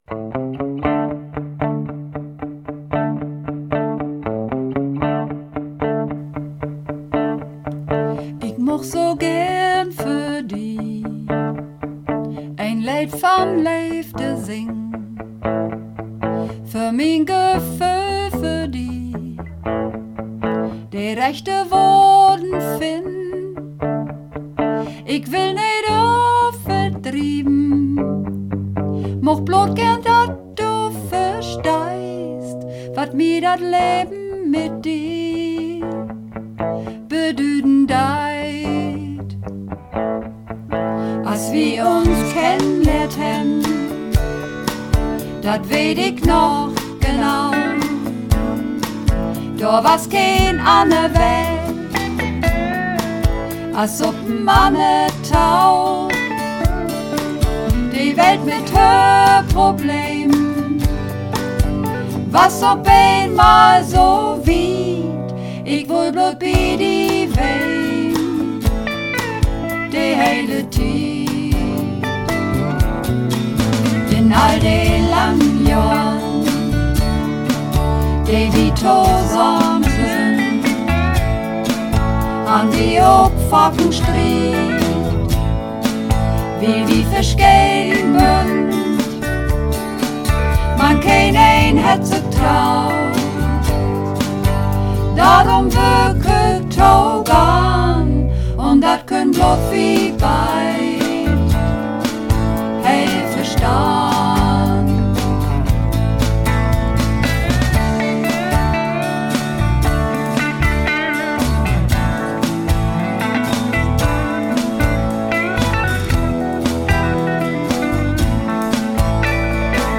Mehrstimmig